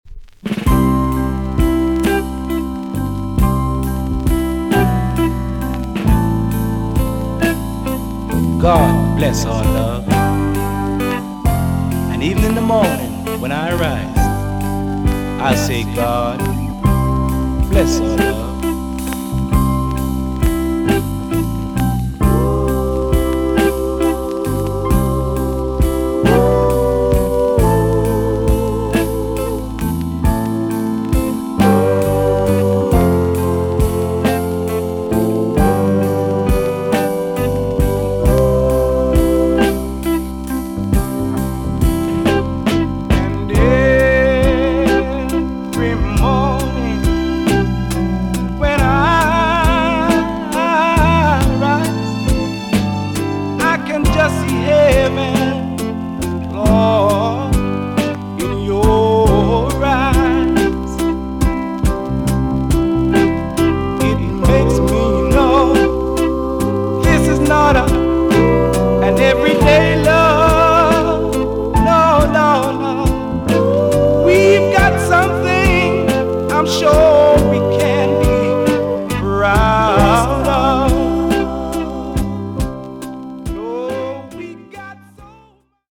TOP >REGGAE & ROOTS
B.SIDE Version
EX-~VG+ 少し軽いチリノイズがありますが良好です。